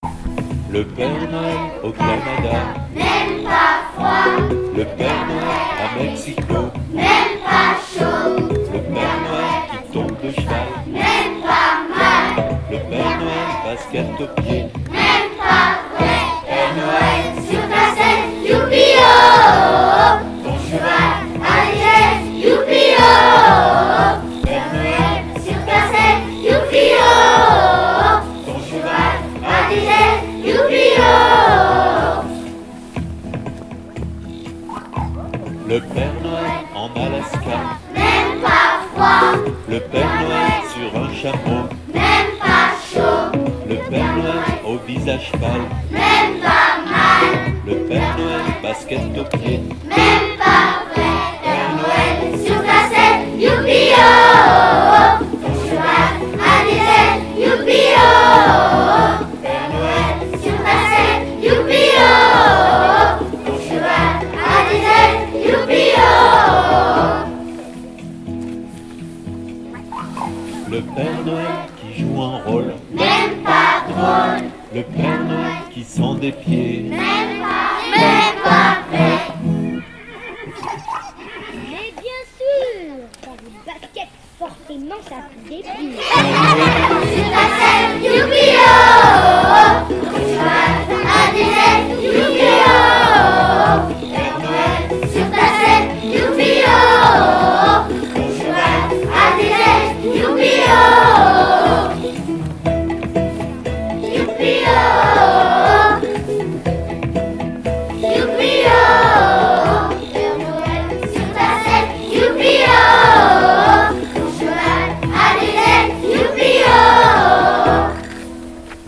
Mini-concert et visite du Père Noël
Les classes de CP et de CE1 ont réalisé un mini-concert pour fêter les vacances à venir.